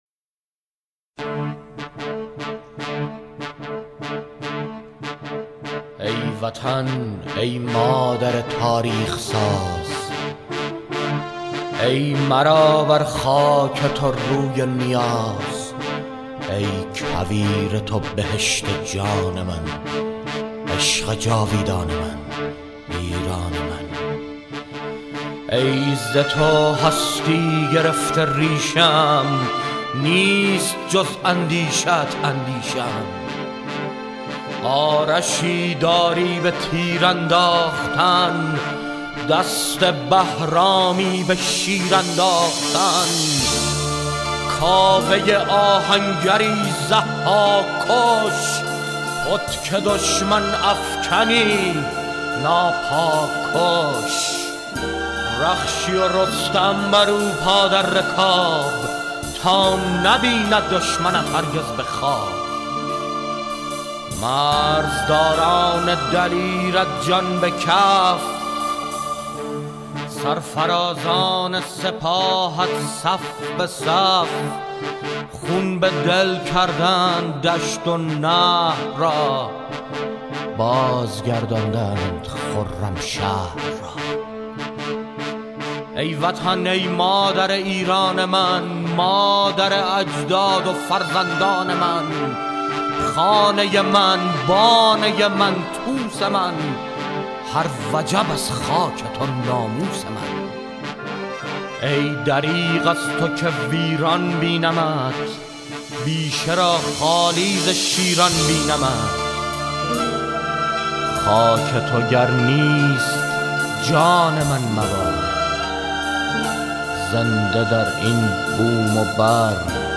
در این فهرست، تعدادی از موسیقی‌های حماسی باکلام